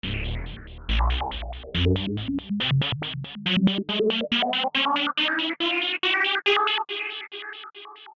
Space Transition Sound Effect Free Download
Space Transition